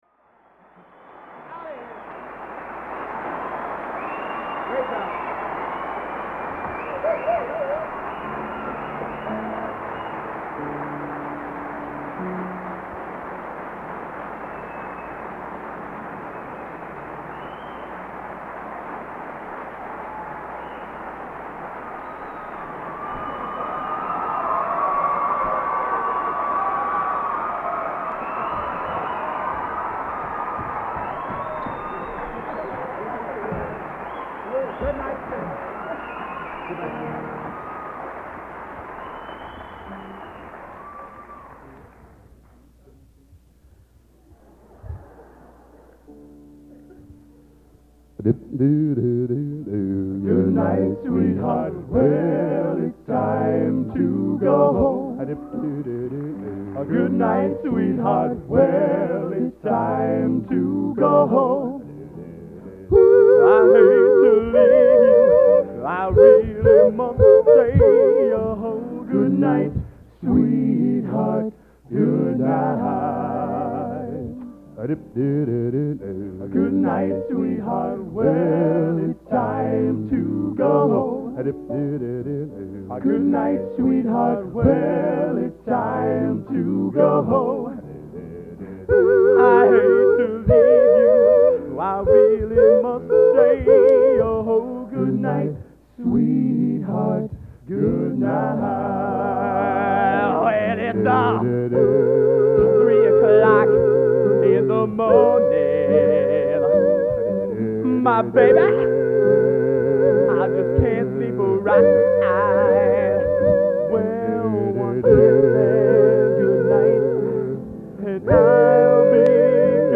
Location: West Lafayette, Indiana
Genre: Doo Wop | Type: End of Season |Specialty